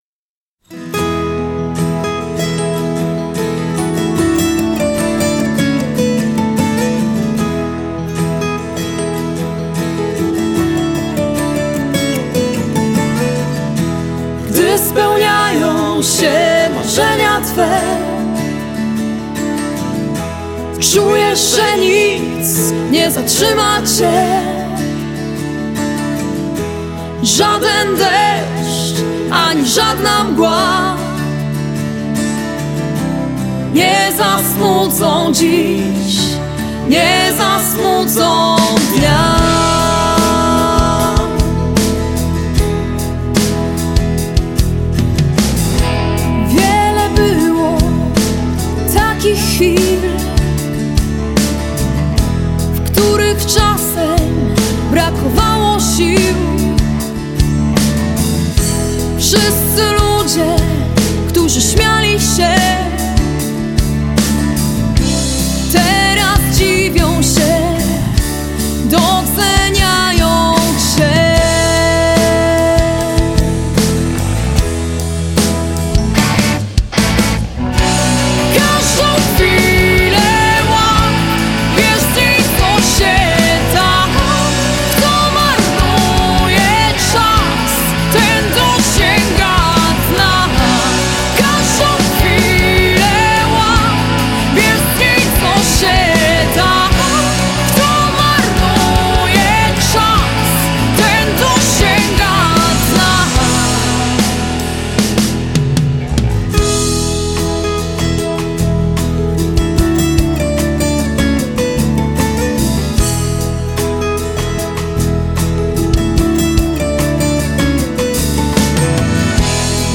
Singiel, który powstał w profesjonalnym studiu nagrań